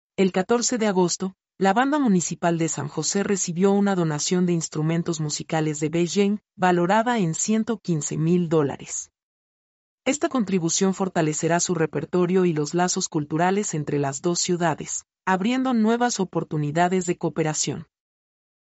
mp3-output-ttsfreedotcom-70-1.mp3